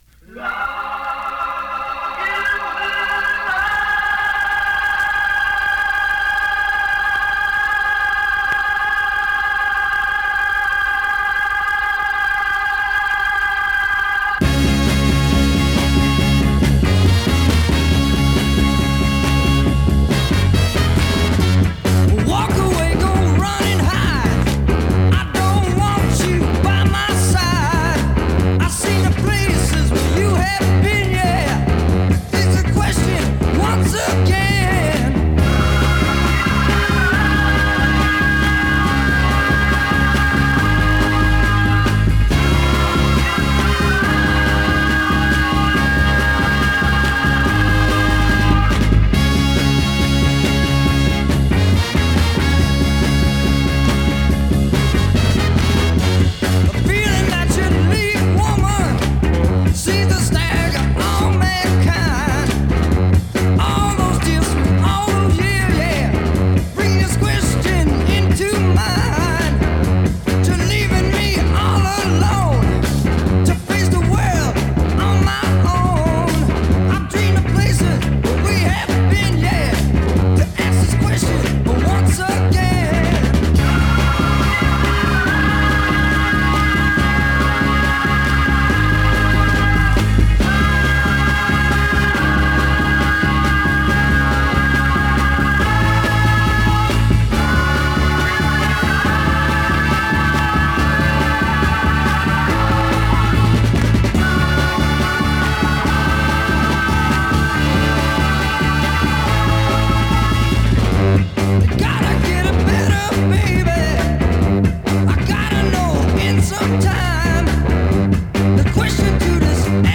Killer psych garage.
試聴 (実際の出品物からの録音です)